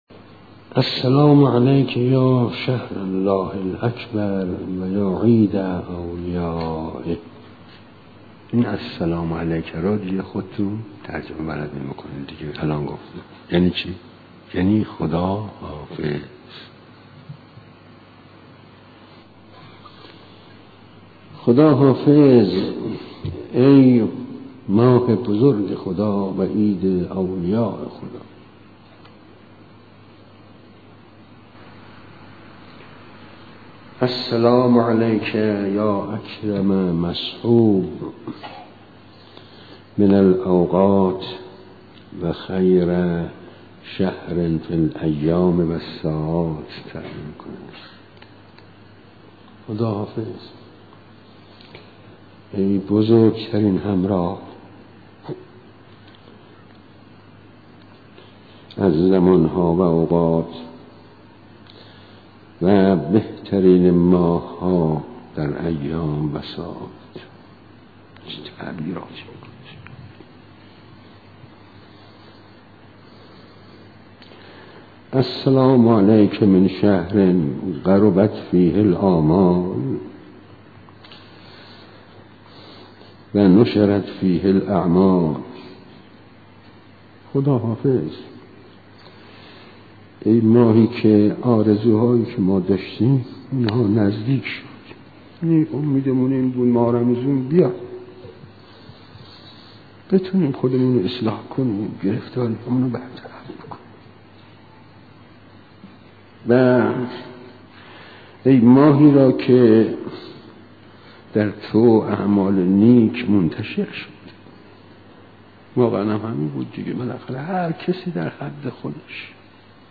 به مناسبت روزهای پایانی ماه مبارک رمضان، فرازهایی از دعای امام سجاد (ع) و بخشی از سخنرانی مرحوم آیت‌الله حاج آقا مجتبی تهرانی که در زمان حیات وی منتشر نشد، تقدیم می‌شود.